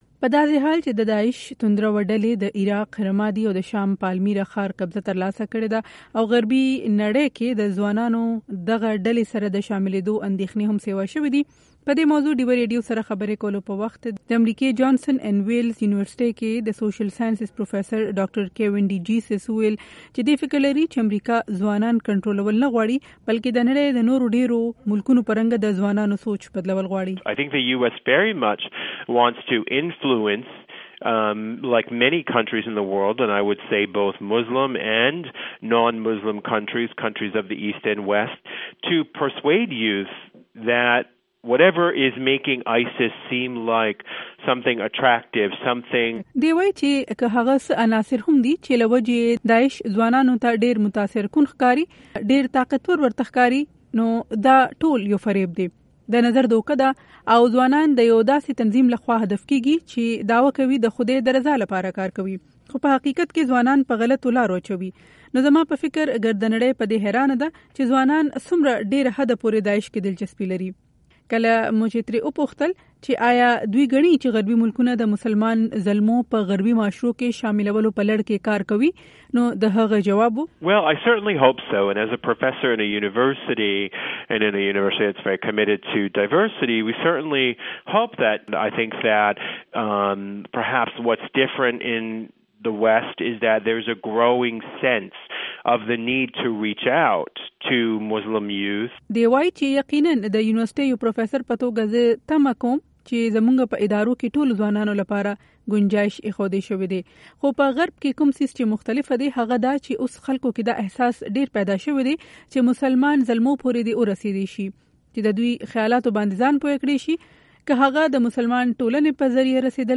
Intv